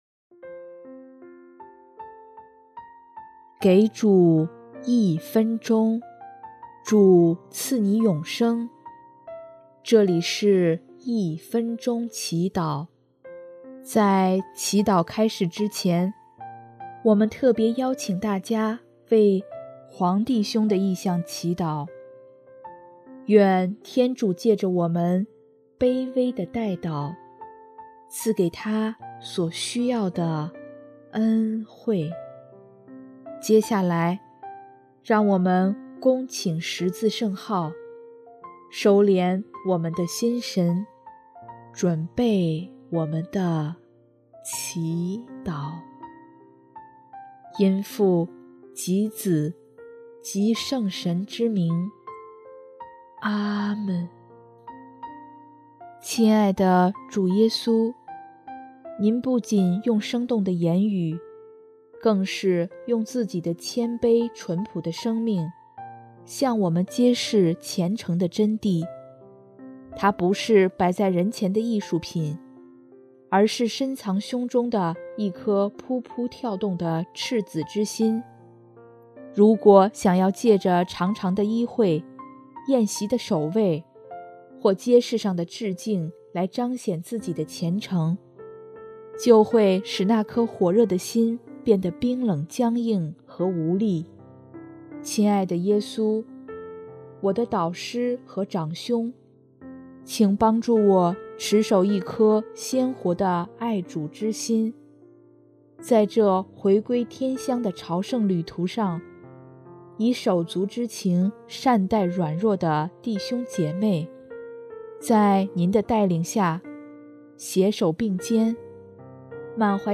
【一分钟祈祷】|3月18日 为叫人看